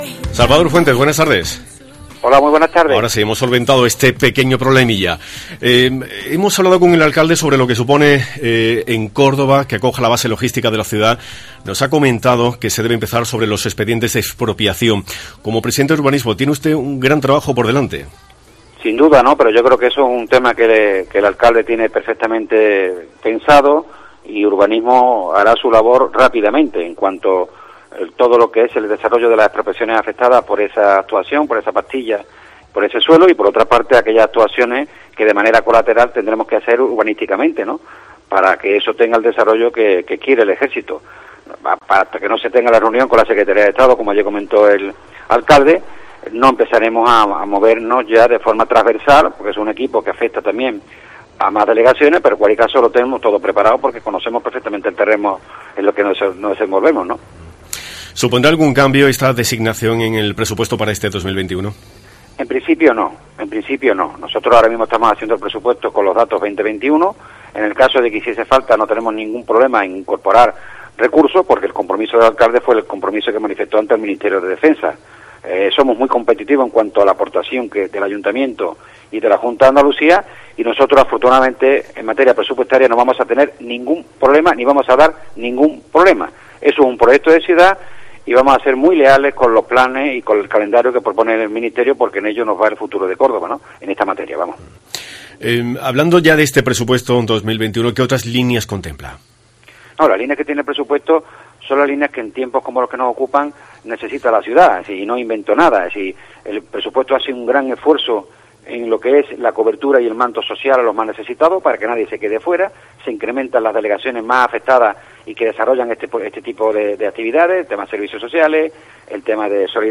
El teniente de Alcalde de Hacienda ha explicado en COPE que ya trabaja en obtener una mayoría suficiente para la aprobación de las cuentas municipales